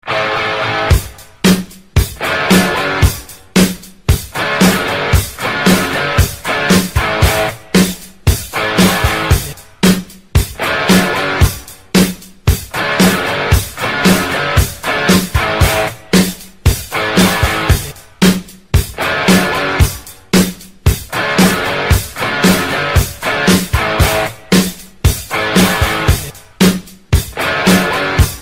Categoría Electrónica